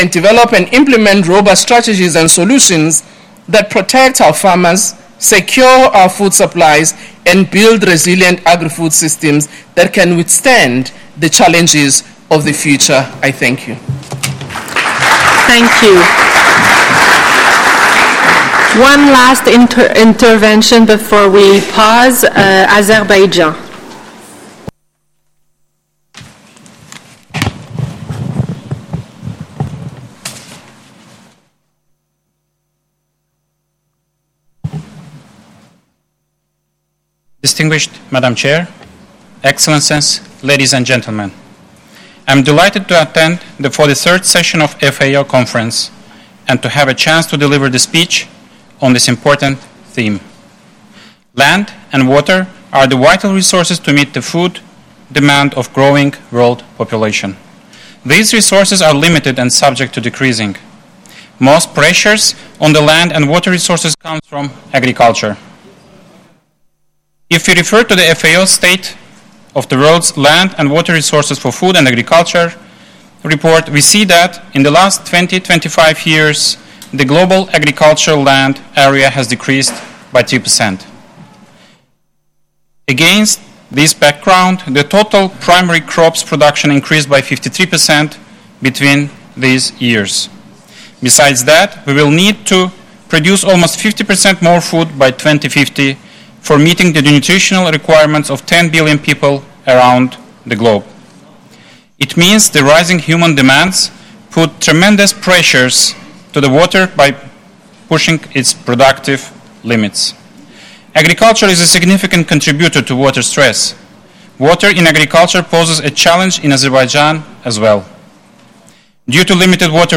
GENERAL DEBATE
Addresses and Statements
H.E. Mmajnun Mamadov, Minister for Agriculture
(Plenary – English)